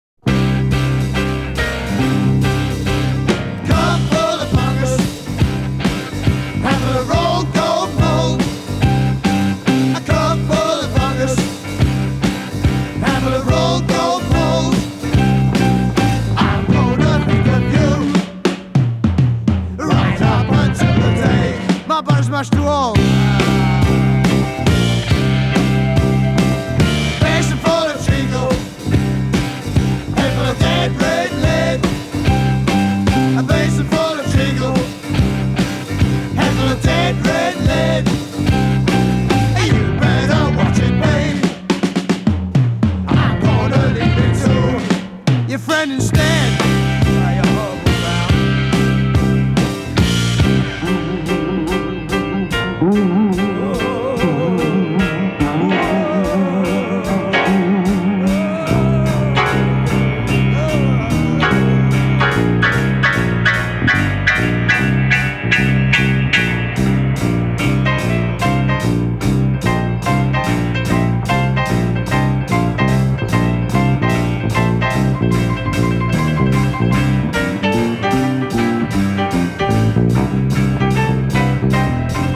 A fusion of jazz, rock and Afro influences